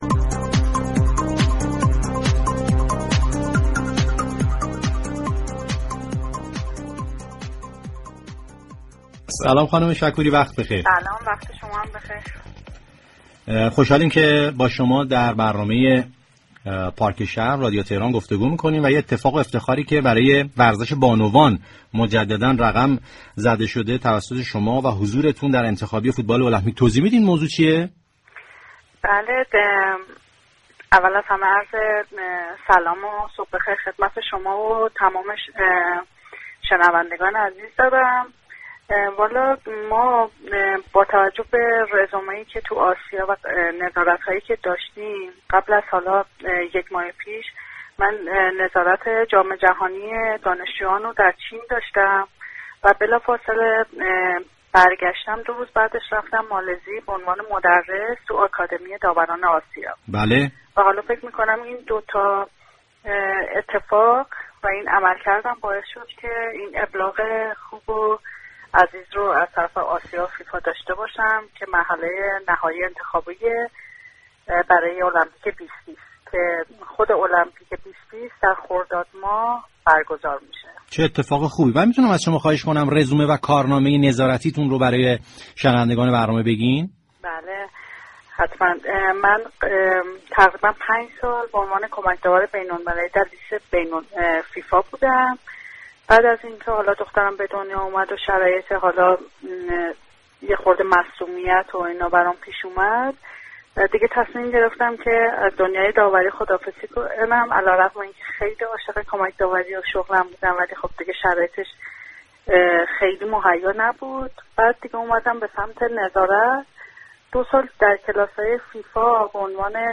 مهمان برنامه پارك شهر بود.